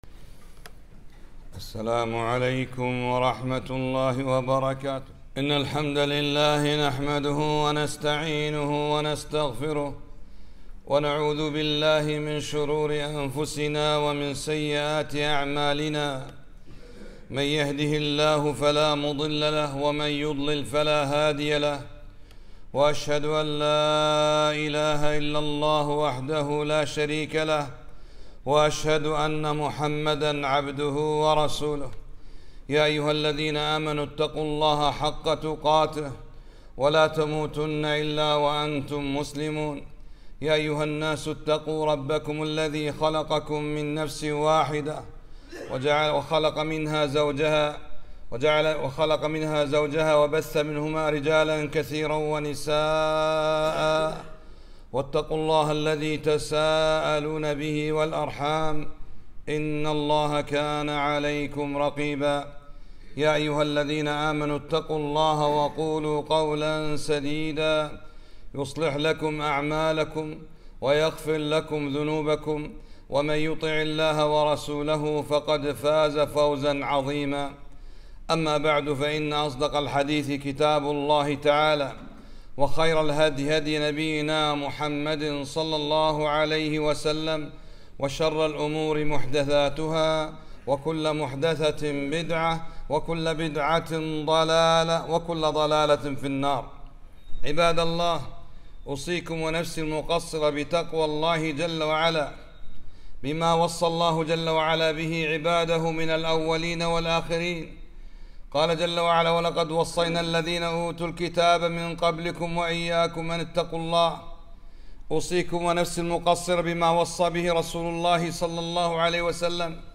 خطبة - البركة من الله عز وجل